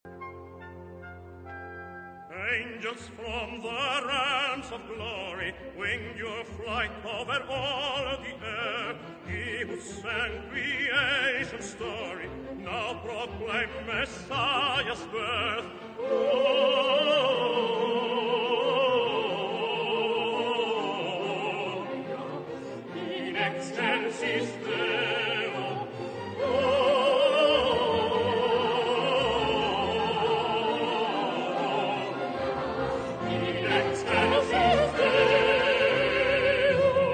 key: F-major